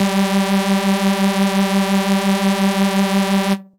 VES2 Dirty Dutch Lead 10 - G3.wav